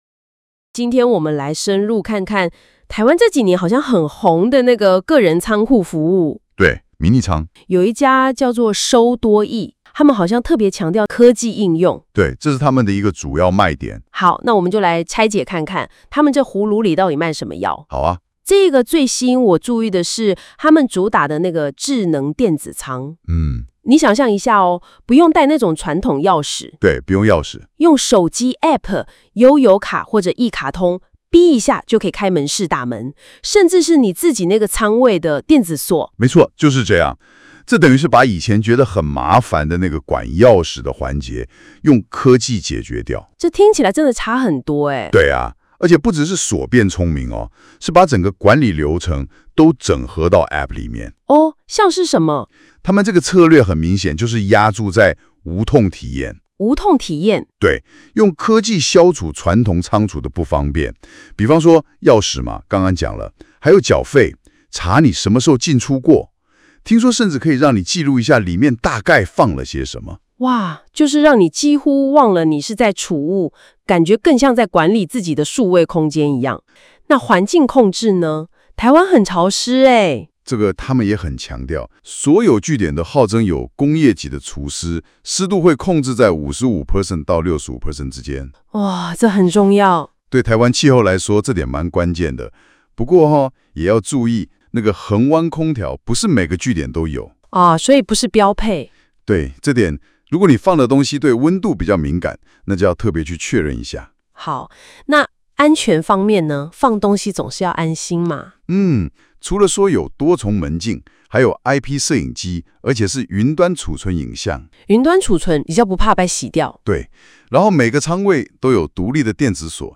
🔊Podcast 語音說明